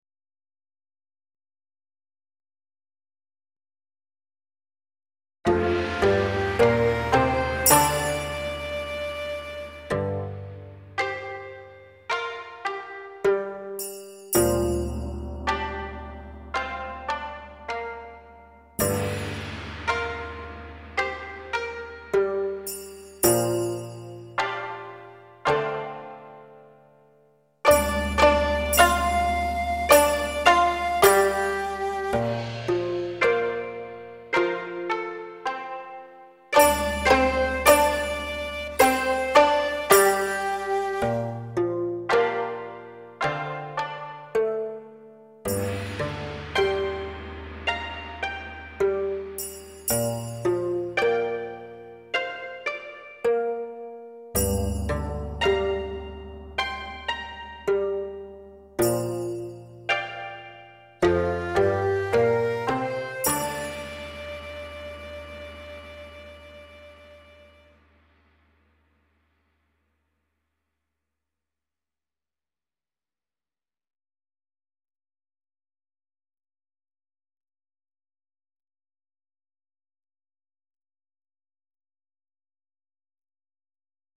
39 Bamboo in the Breeze (Backing Track)